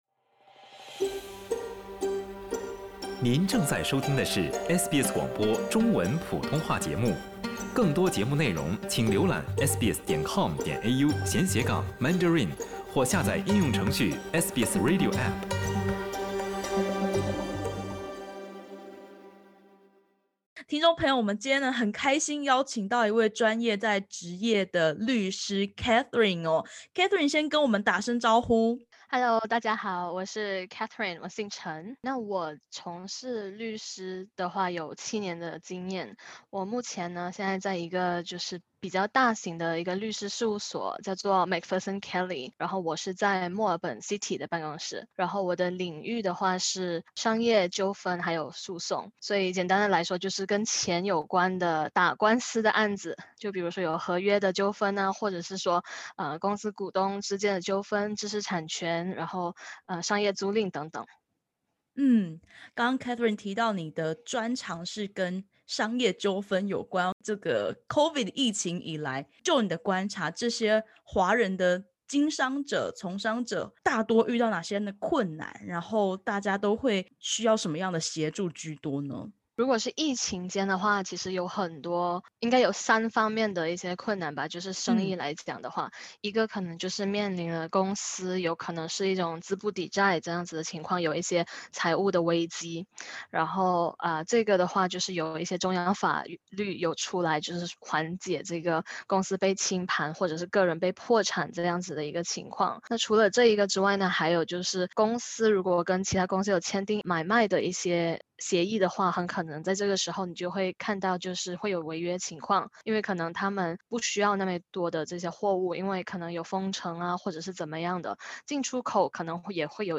年终岁末，澳大利亚许多因应疫情而产生的商业临时法条即将到期，澳华从商者、商业租客们该何去何从？点击首图收听完整采访音频。